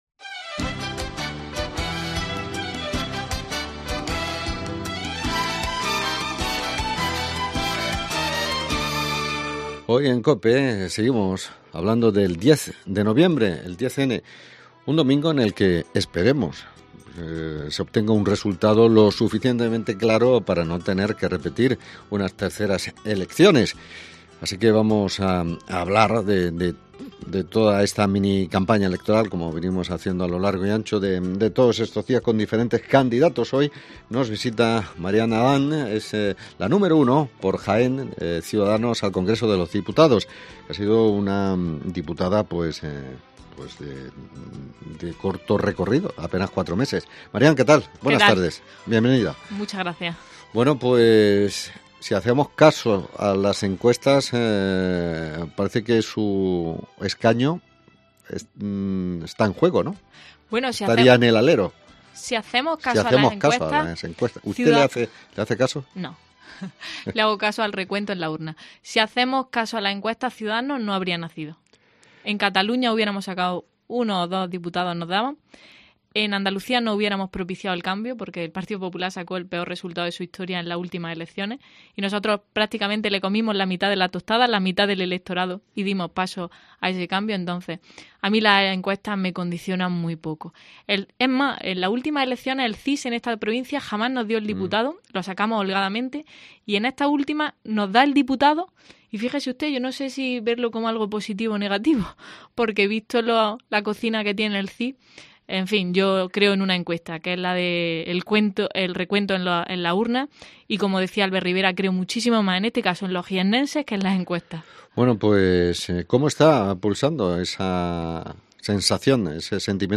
Hoy en Cope ha estado la cabeza de lista de C's al Congreso de los Diputados por Jaén, Marián Adán que confía en revalidar su escaño el próximo domingo